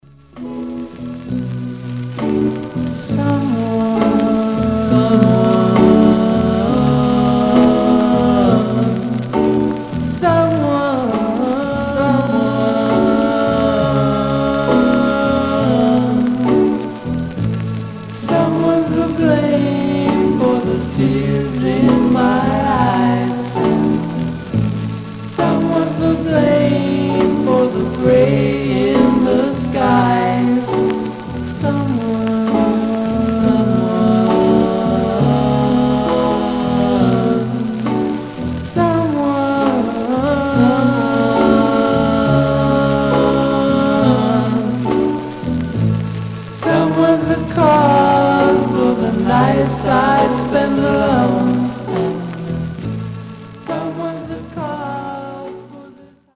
Much surface noise/wear Stereo/mono Mono